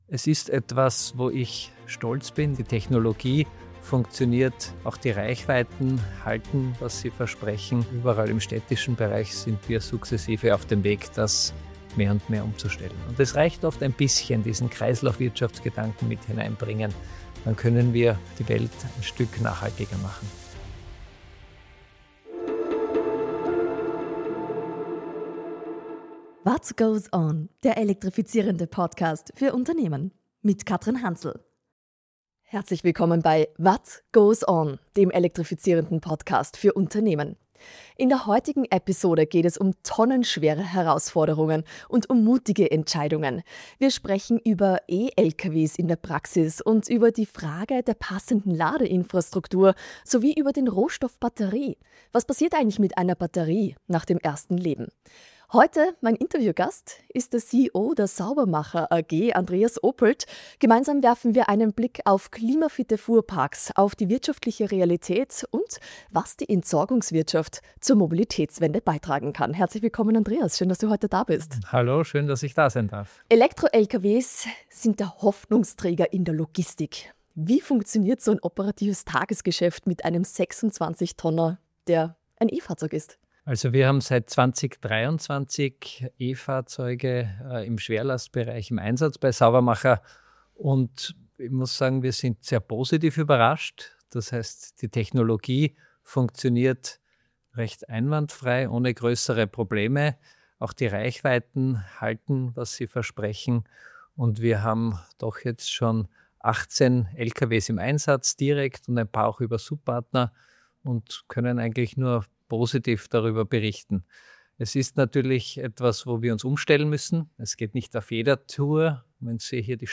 Im Gespräch geht es außerdem um Batterien als Wertstofflager, um Second Life Speicher, Recyclingprozesse und die Bedeutung von Standardisierung für eine funktionierende Kreislaufwirtschaft.